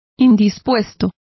Complete with pronunciation of the translation of indisposed.